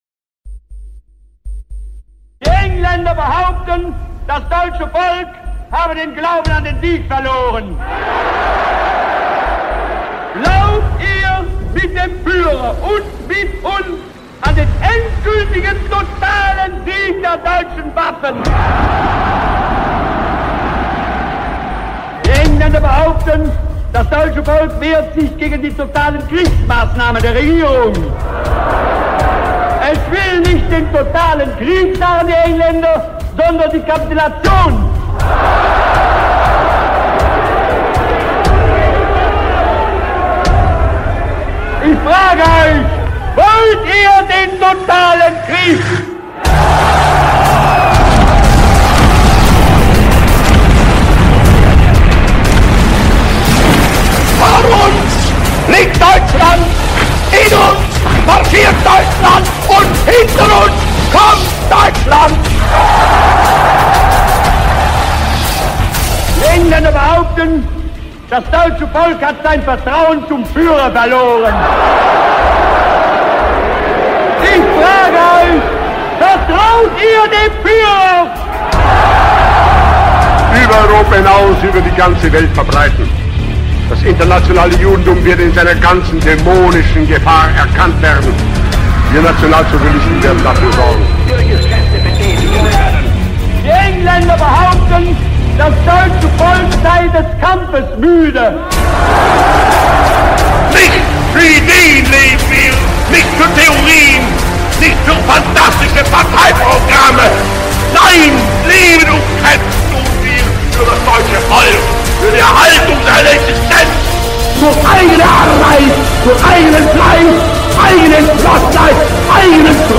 Sounds with the voice of Adolf Hitler download and listen online